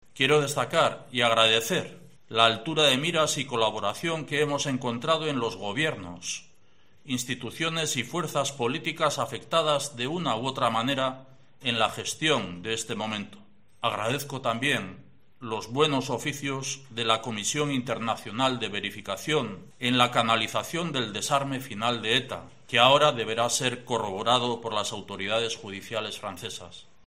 El presidente vasco ha dedicado a las víctimas de la organización terrorista las primeras palabras de su solemne declaración, en la que ha considerado que "todas y cada una" de ellas son  "sujetos y partícipes principales de este logro democrático".